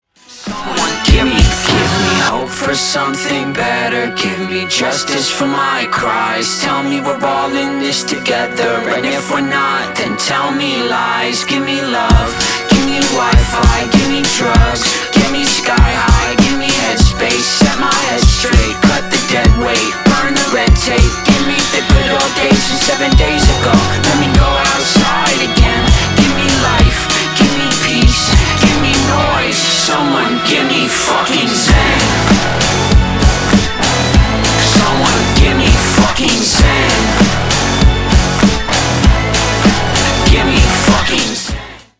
громкие
жесткие
Драйвовые
Electronic
alternative
indie rock